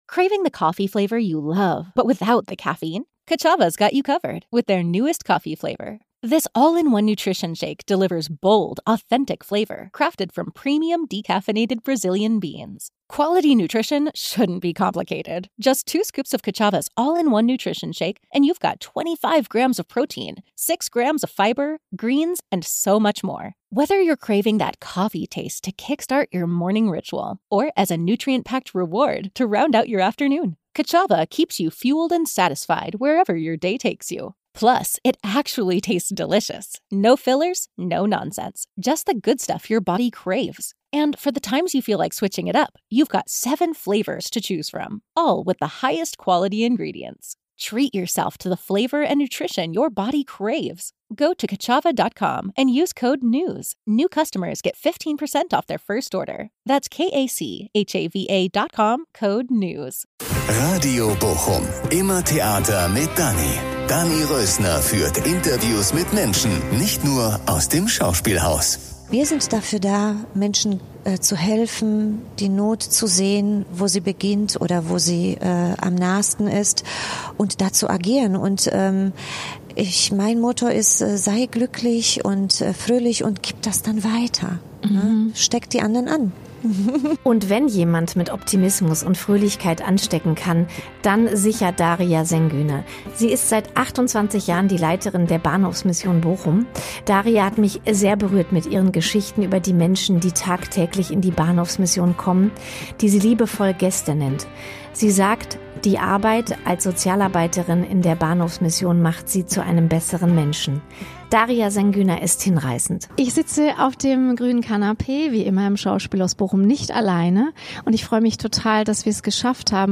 auf dem grünen Canapé im Schauspielhaus.